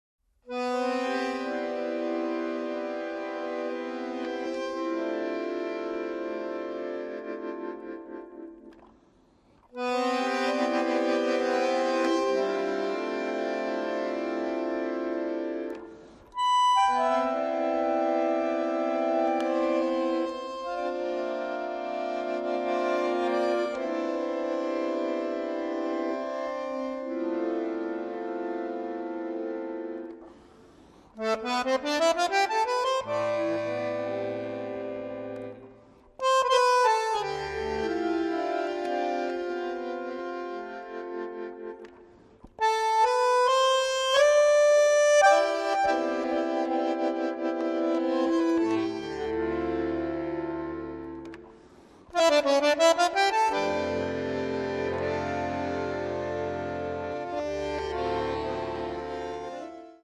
tromba, flicorno
sax soprano
pianoforte
contrabbasso
batteria
bandoneon